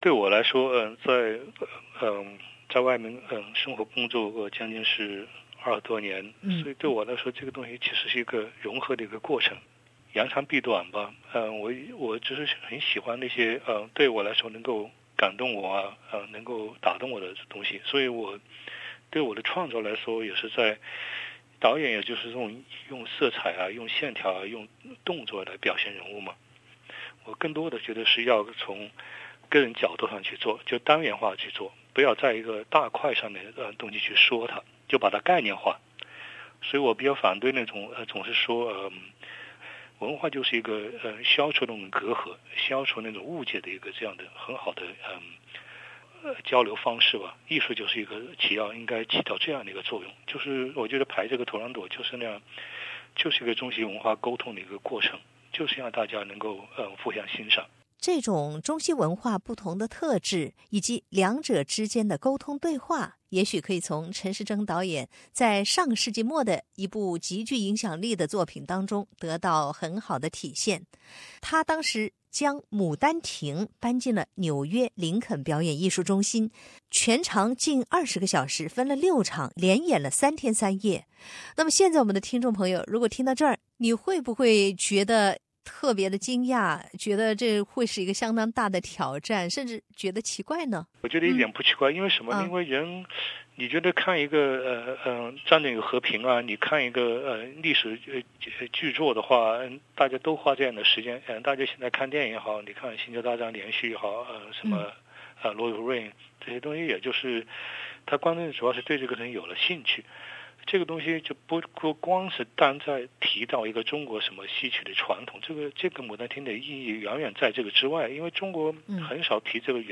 图兰朵的生命能延续多久？专访陈士争导演